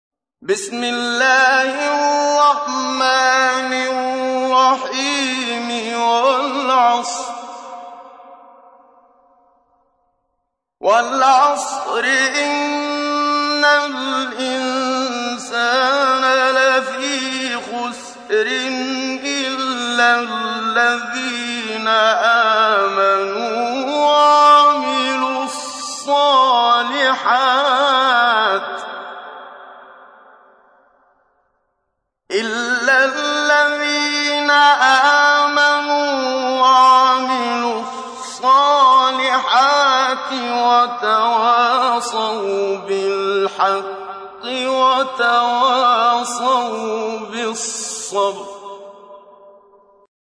تحميل : 103. سورة العصر / القارئ محمد صديق المنشاوي / القرآن الكريم / موقع يا حسين